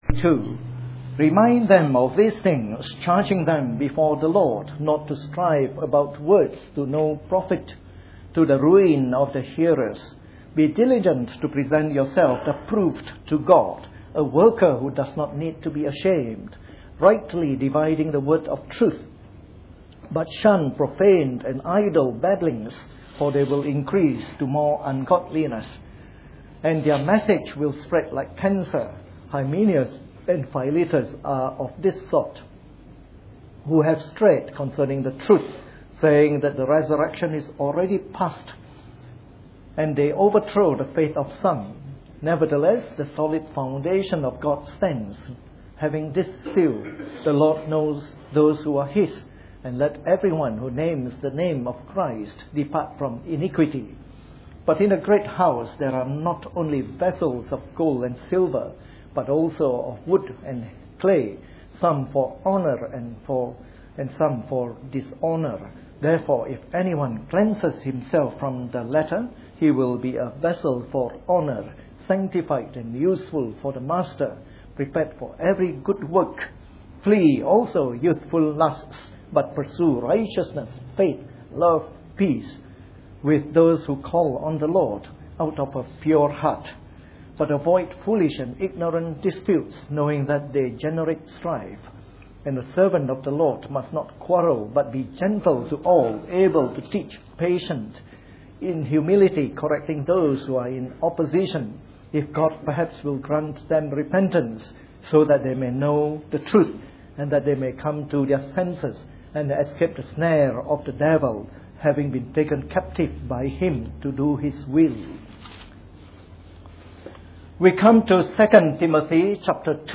A sermon in the morning service from our series on 2 Timothy.